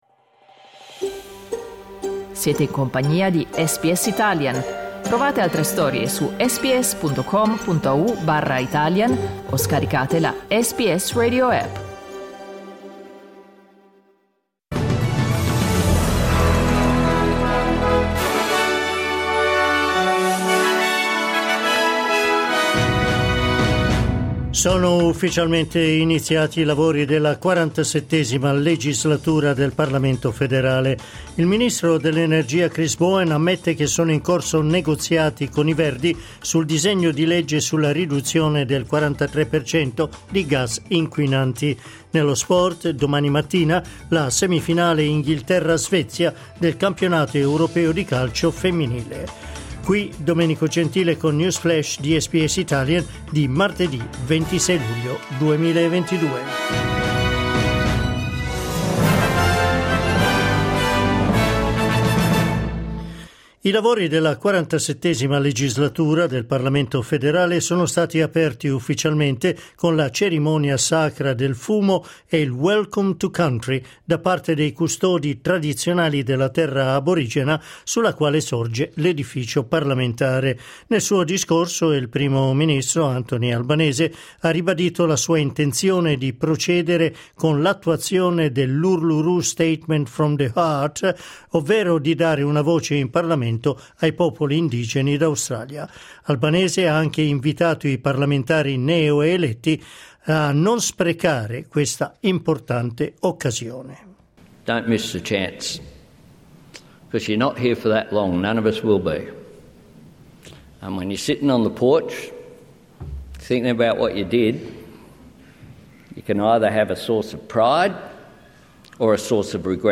News flash martedì 26 luglio 2022